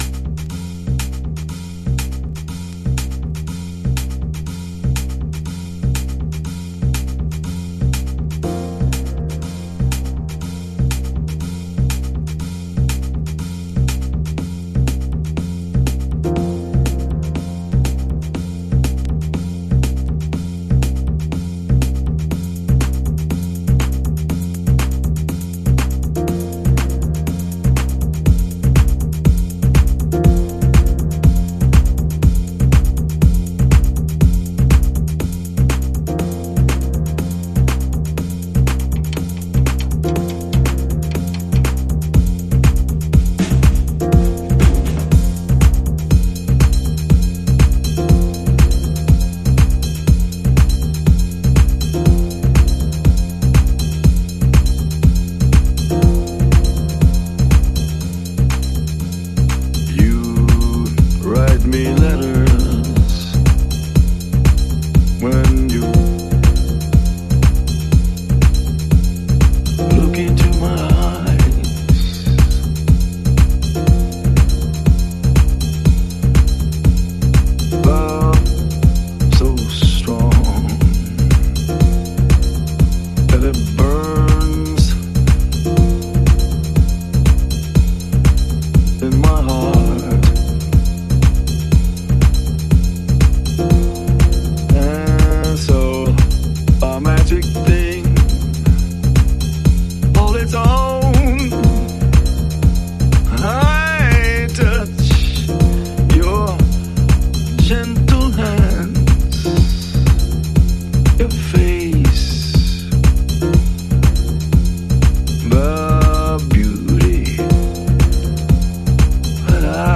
House / Techno
さり気にサイケデリックなオーケストラジャズ、しなやかに揺らぐディープハウス。